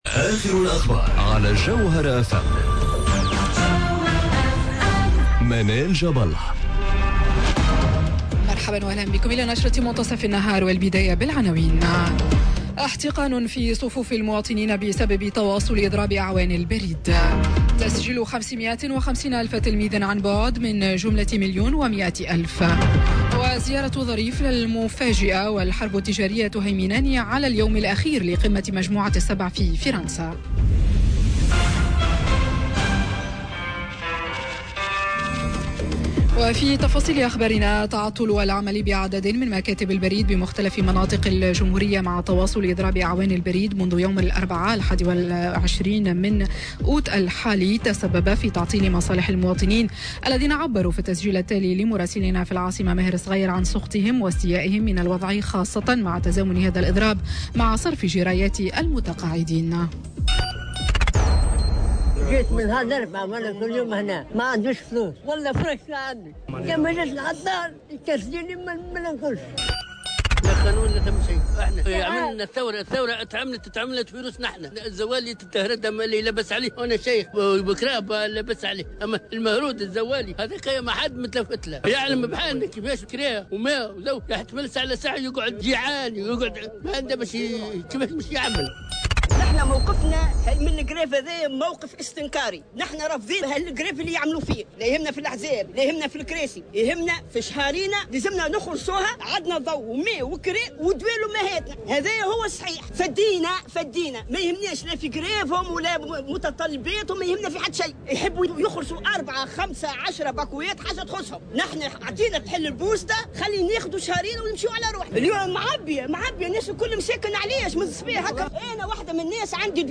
نشرة أخبار منتصف النهار ليوم الإثنين 26 أوت 2019